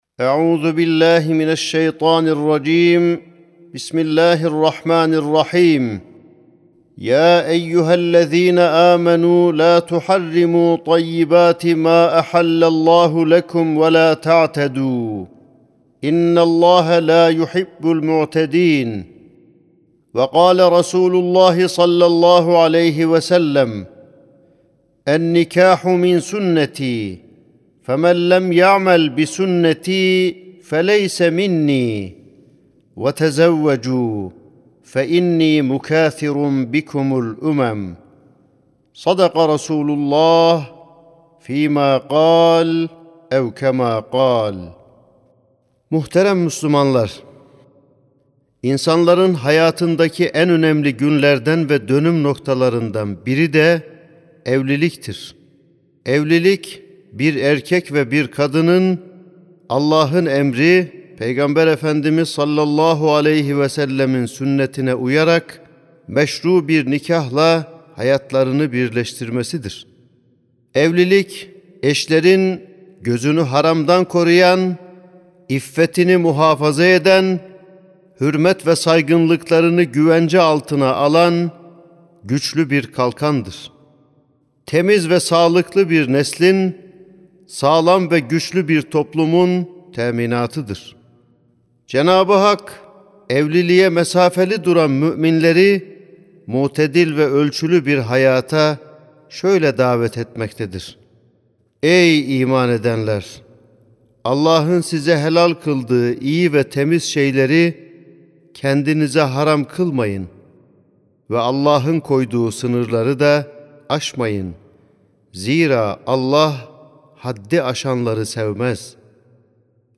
13.06.2025 Cuma Hutbesi: Düğün Yapmanın da Bir Âdâbı Vardır (Sesli Hutbe, Türkçe, İngilizce, Rusça, Fransızca, Arapça, İspanyolca, Almanca, İtalyanca)
Sesli Hutbe (Düğün Yapmanın da Bir Âdâbı Vardır).mp3